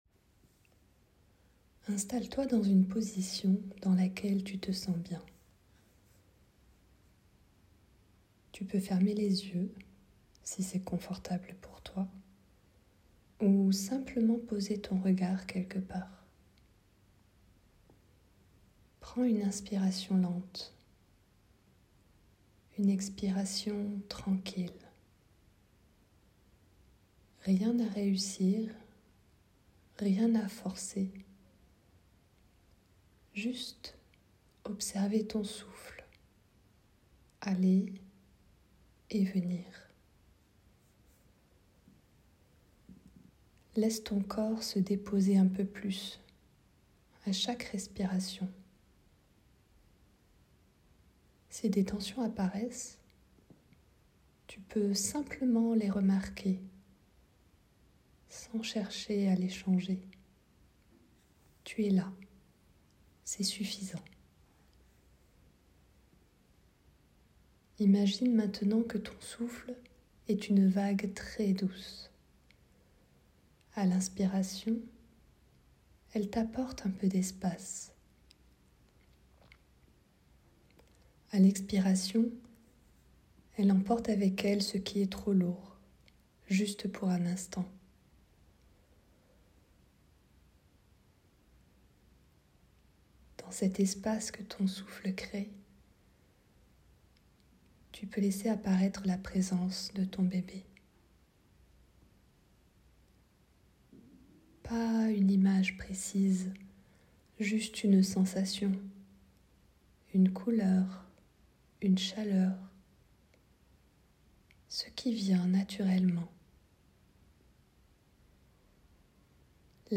Méditation guidée _ la vague
Meditation-guidee-_-la-vague.wav